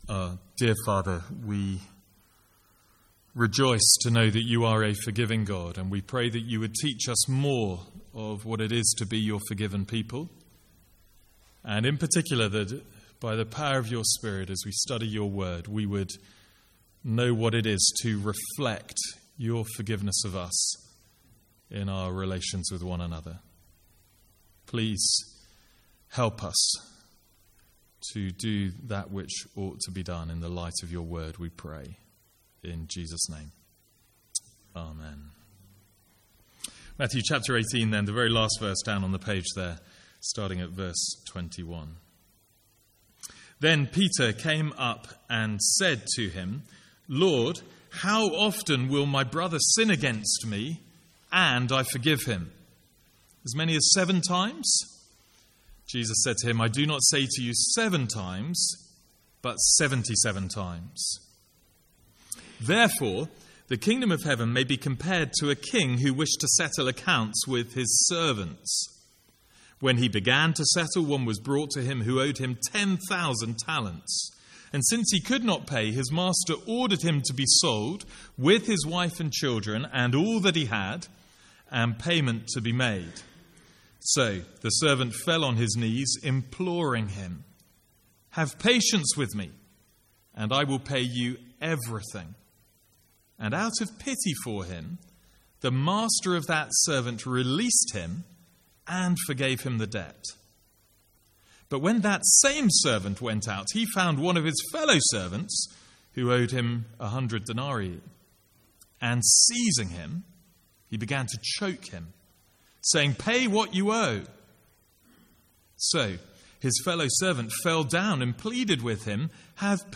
From the Sunday evening series 'Hard sayings of Jesus'.